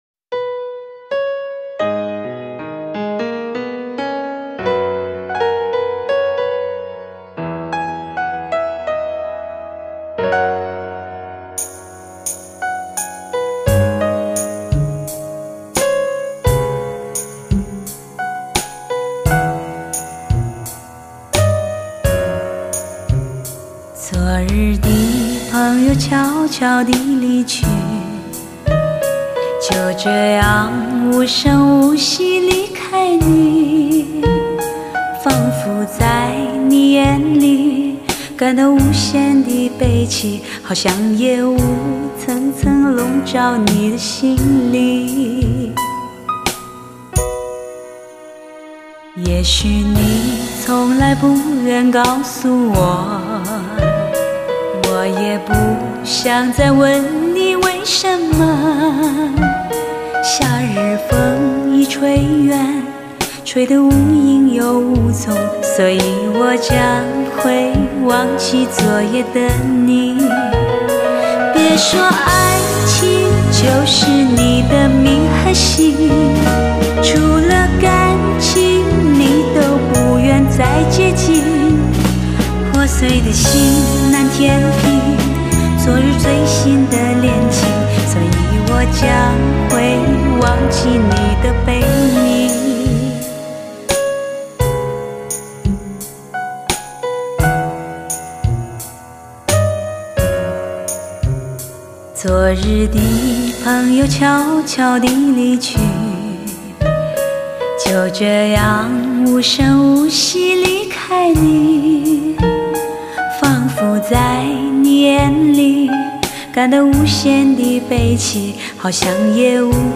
类型: 天籁人声
那透明晶莹的钢琴伴奏是否像涧中溪流般沁人心脾？
穿墙破壁；乐器层次清晰，远近分隔度堪称无敌，音色鲜活，格调清新，质朴至极，定位感之强烈叫人